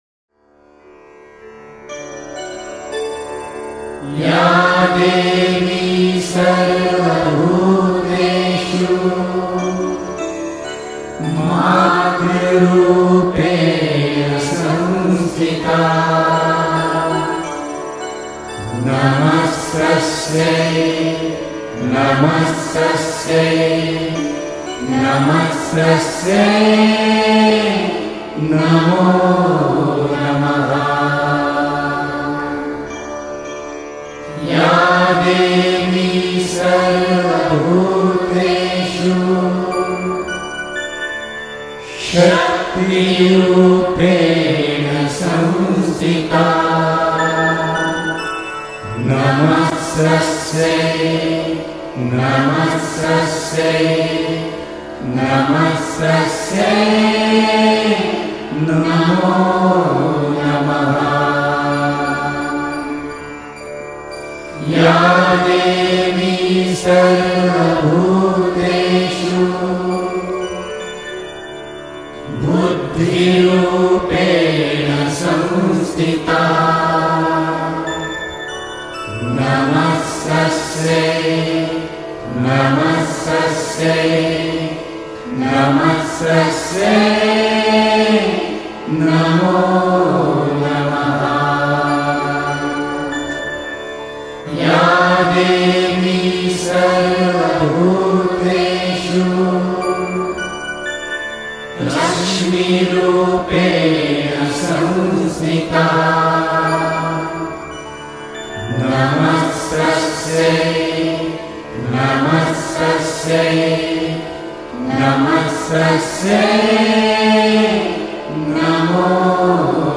Queremos celebrar el comienzo de Navaratri (la nueve noches dedicadas a la Diosa) con la recitación del Devi Suktam, un precioso canto dedicado a la Madre Divina que es una sección del Devi Mahatmyam, en el cual se adora a la Diosa como la esencia de todos los aspectos del universo.
Ya-Devi-Sarva-Bhuteshu-Ma-Durga-Mantra-108-Times-Divine-Mantra-Most-Powerful.mp3